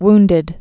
(wndĭd)